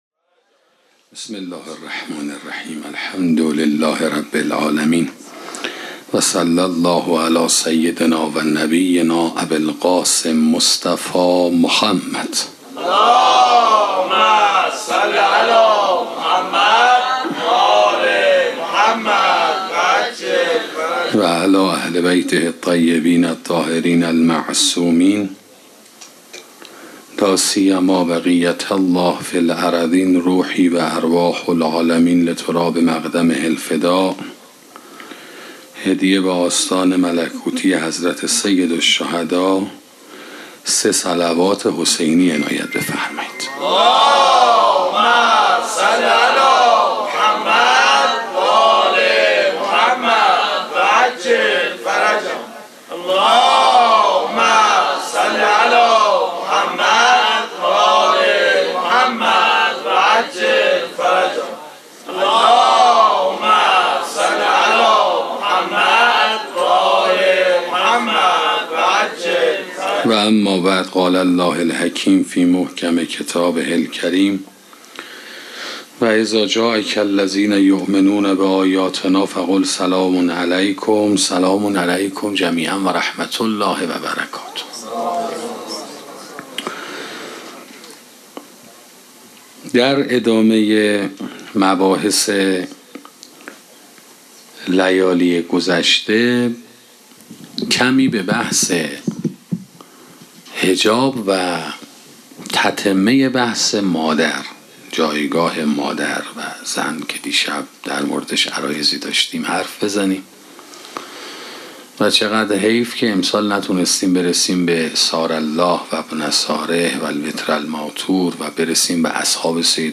سخنرانی جایگاه معنوی مادر 6